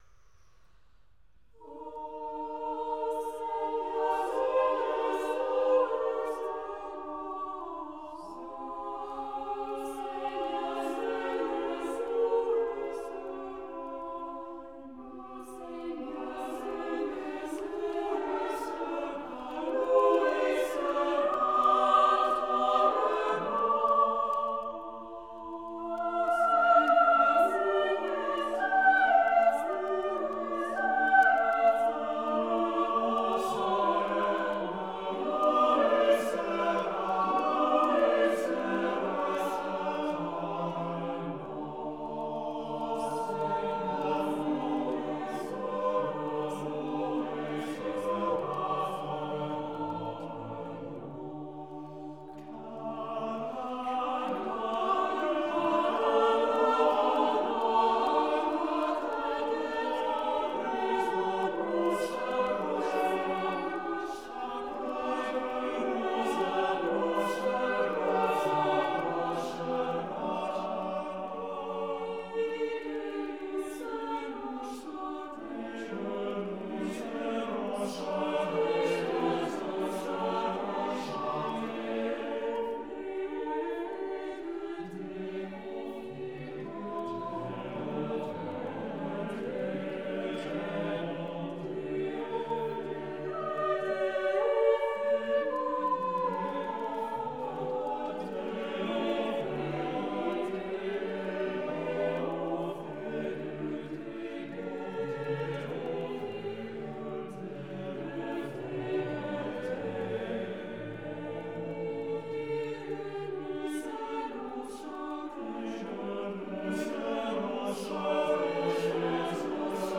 accomplished chamber choir